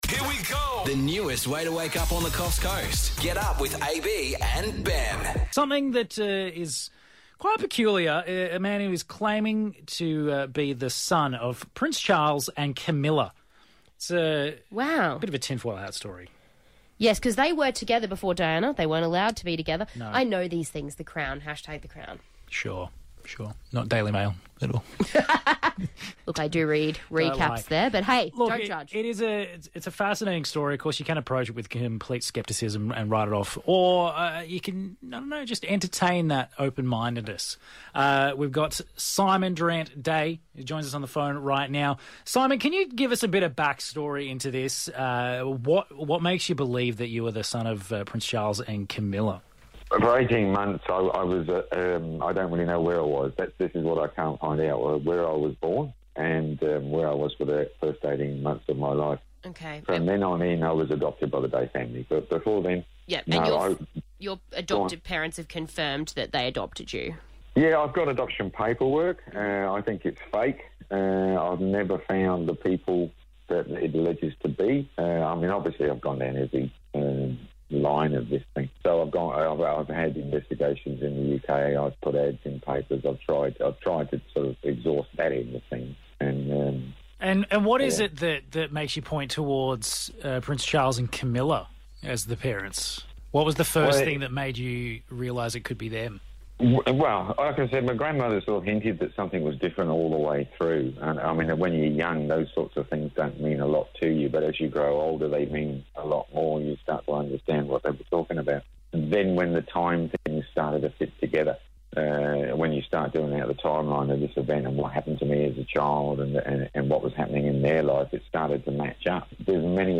Interview - Coff's Coast Hit 105.5